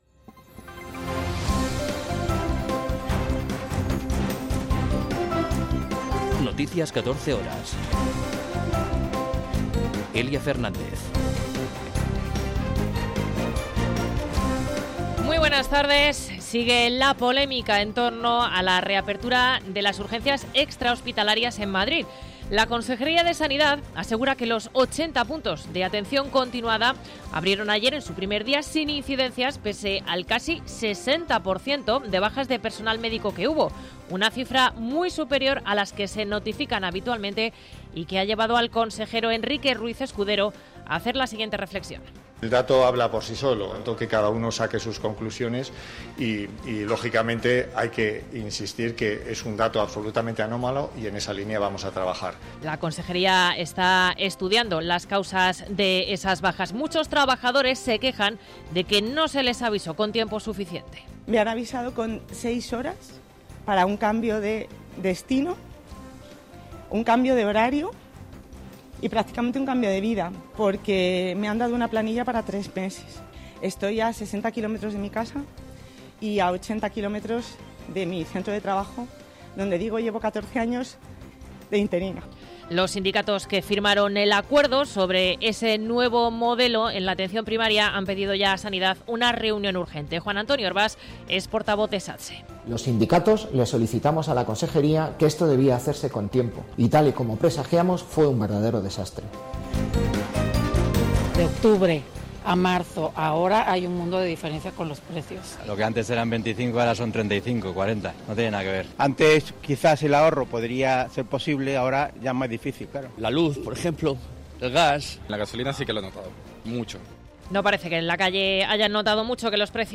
Noticias 14 horas 28.10.2022